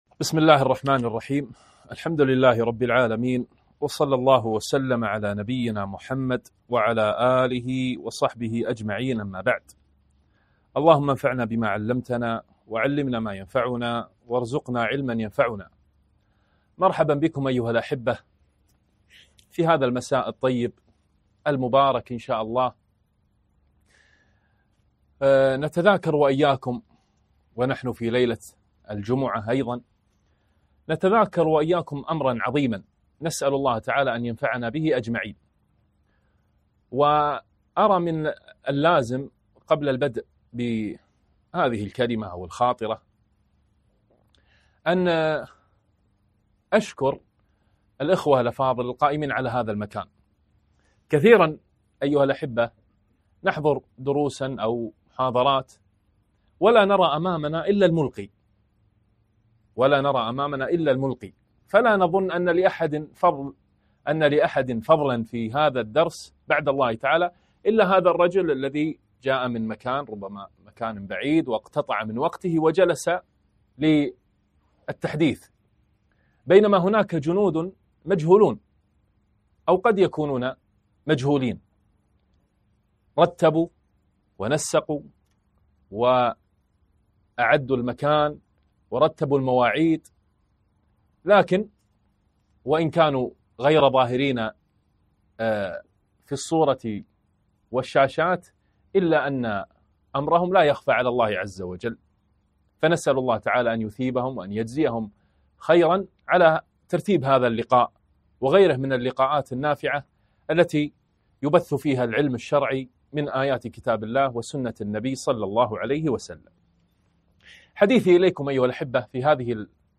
محاضرة - اقترب رمضان